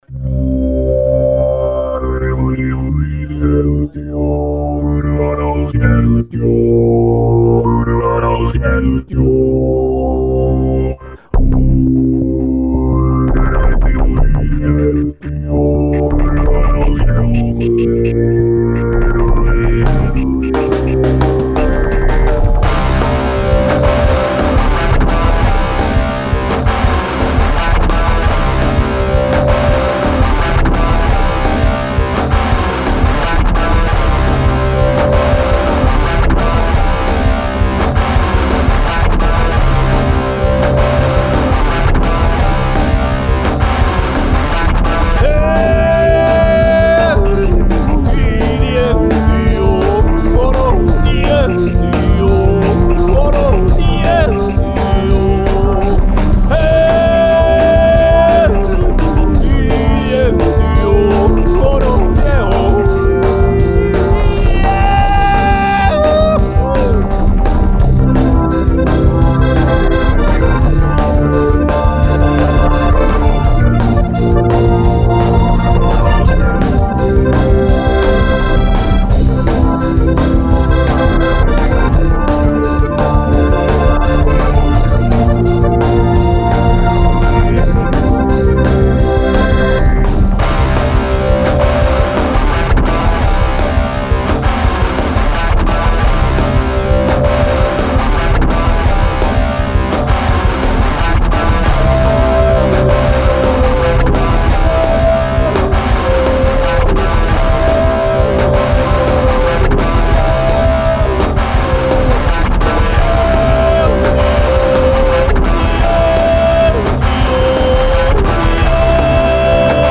PopMix